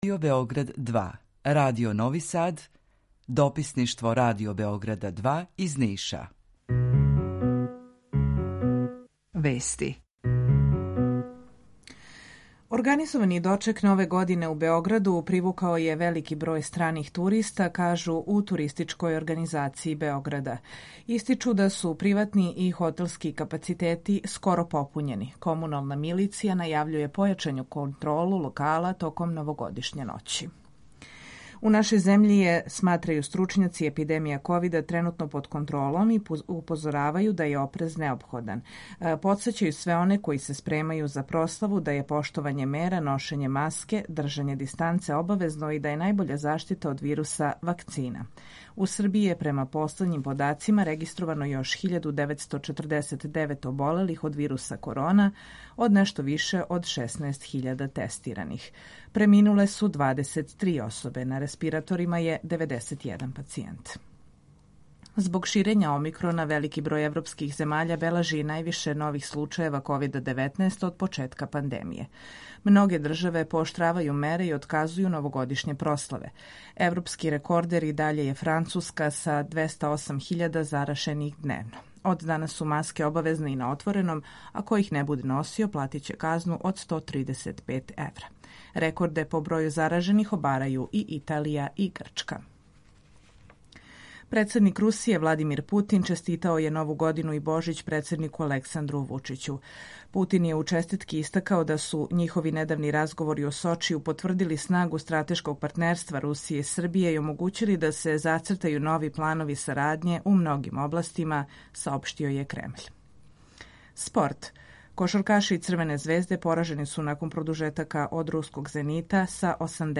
Емисију реализујемо заједно са студијом Радија Републике Српске у Бањалуци и Радијом Нови Сад РТВ.
У два сата, ту је и добра музика, другачија у односу на остале радио-станице.